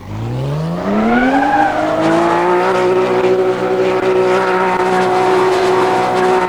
Index of /server/sound/vehicles/vcars/porsche911carrera
reverse.wav